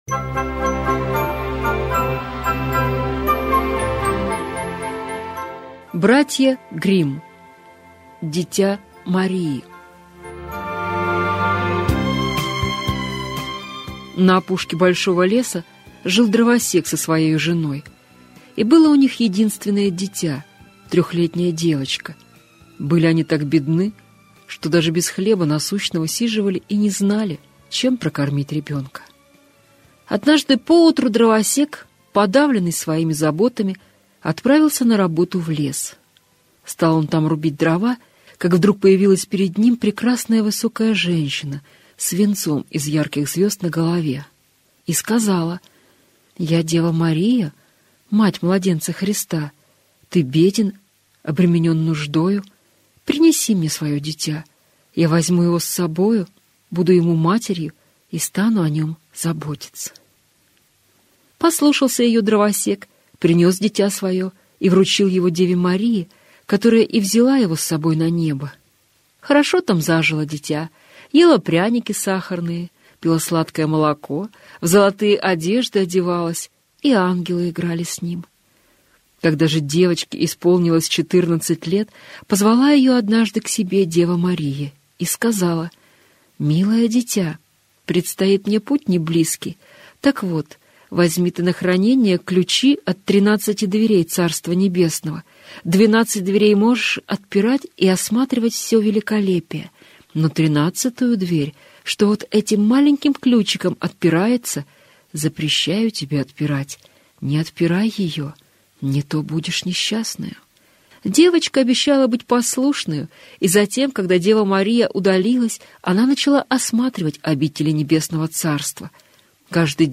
Дитя Марии - аудиосказка Братьев Гримм - слушать онлайн